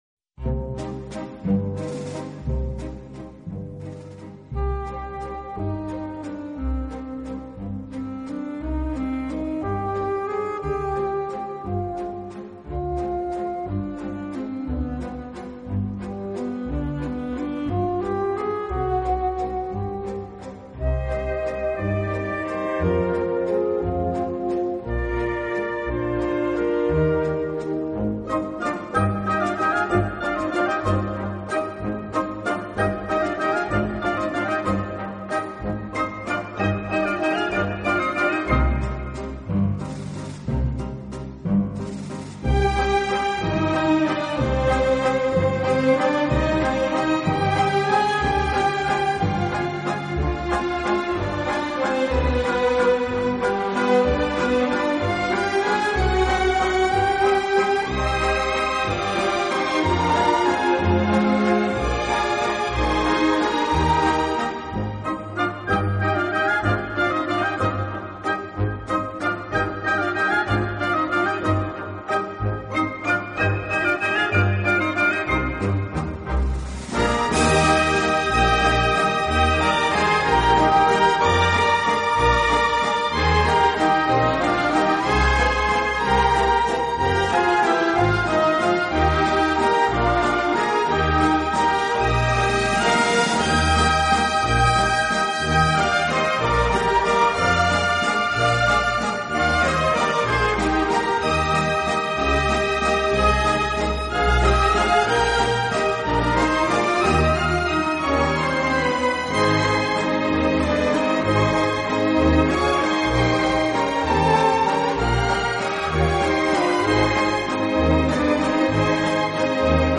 边拉小提琴边指挥乐队。